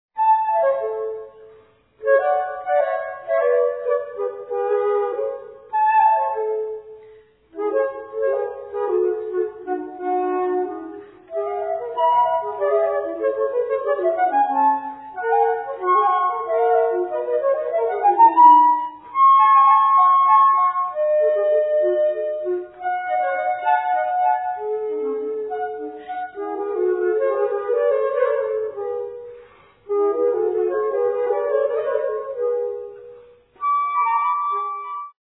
sonata for 2 flutes No. 5 in B flat major
Moderato - 3:07